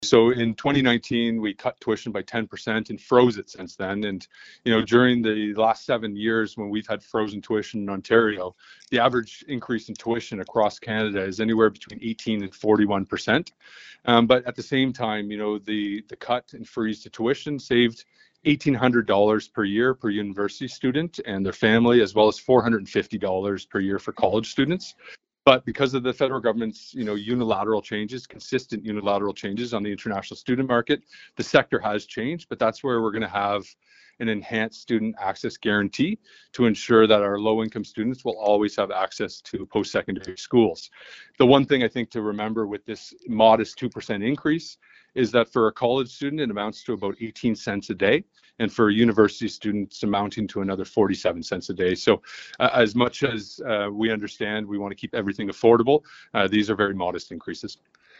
Quinn explains the reasoning for this approach.